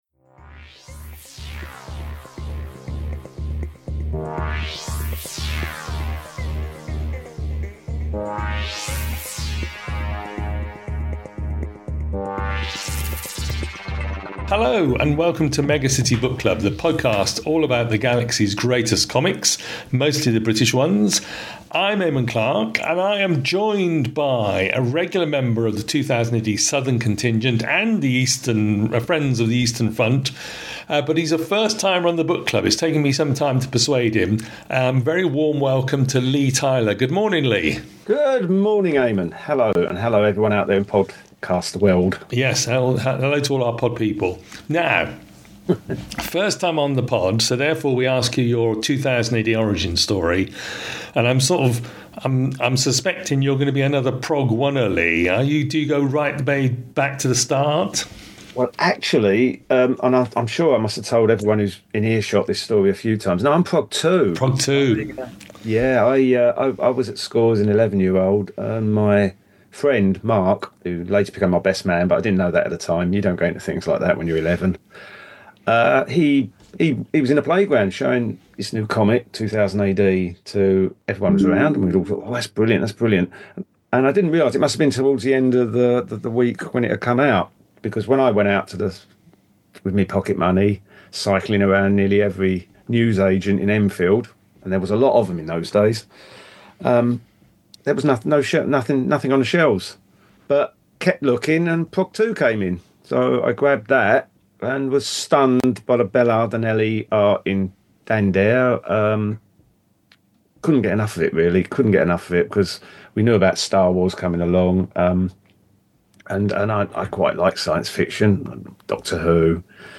is joined by a different guest to discuss a 2000AD book of their choice